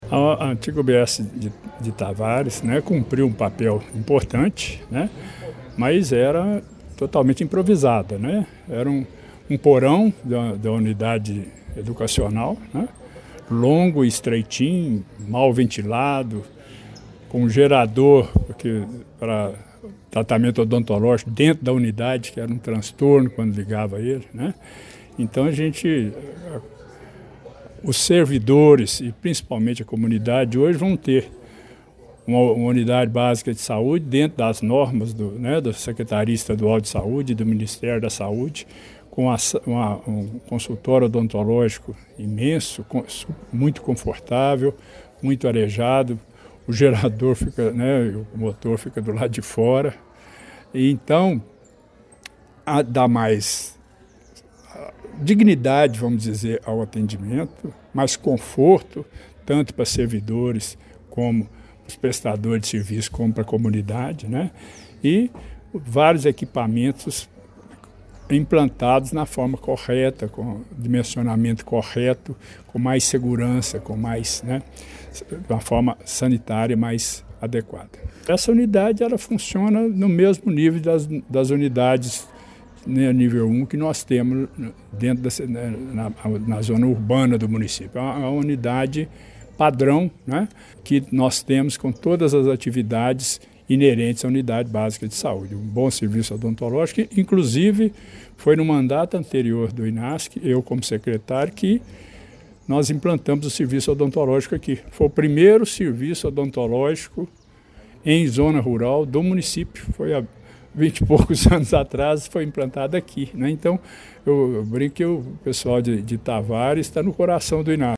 O secretário municipal de Saúde, Gilberto Denoziro Valadares da Silva, ressaltou o contraste entre a antiga e a nova UBS, destacando o ganho em dignidade, conforto e qualidade do atendimento.